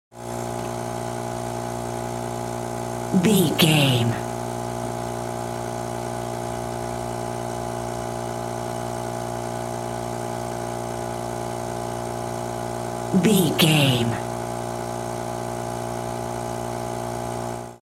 Sound Effects
urban
chaotic
anxious